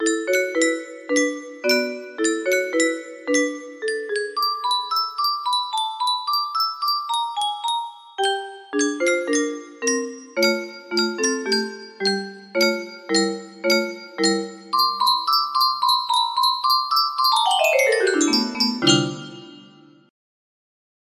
shiiit music box melody